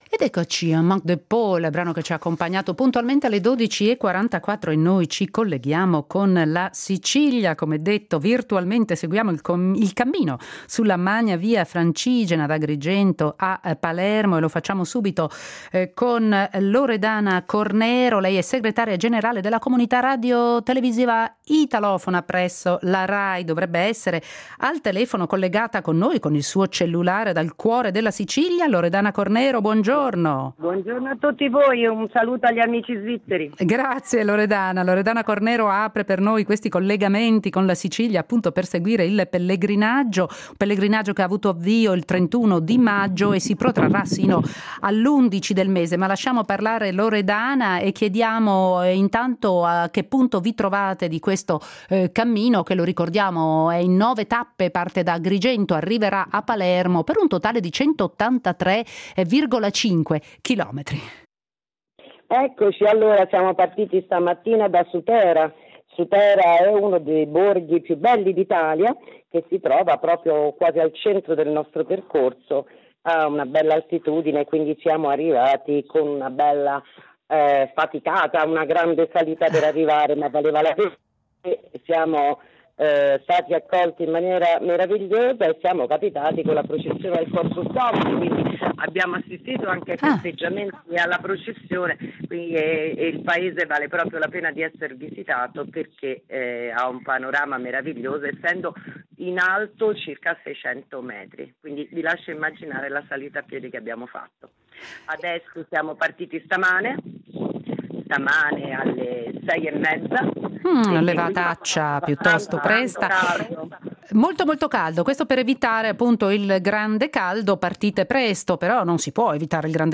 In diretta con i viandanti del Pellegrinaggio di Via Francigena.